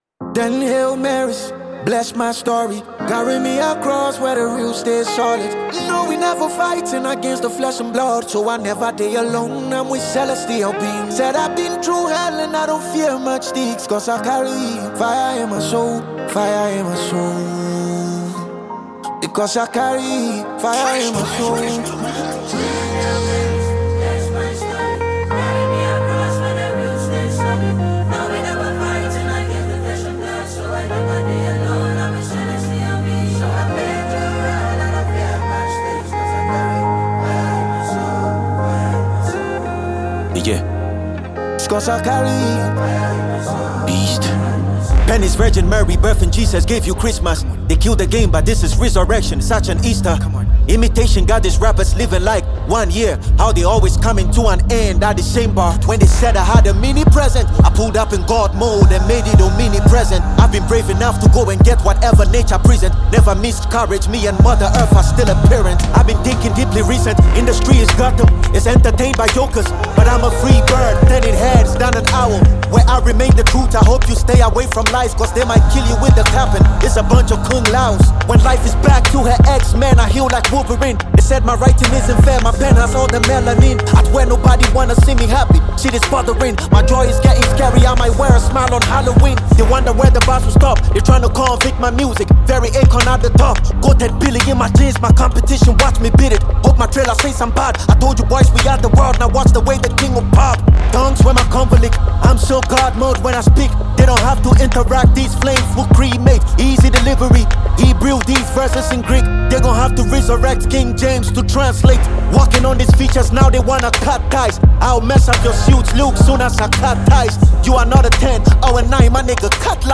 a Ghanaian creative rapper